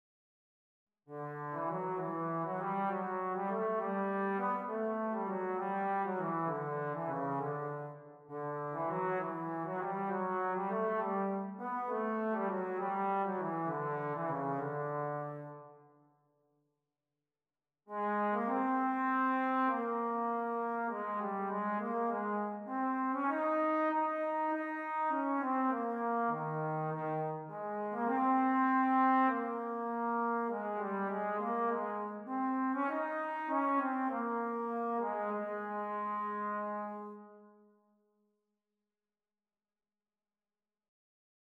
Any Bass Clef Brass Studies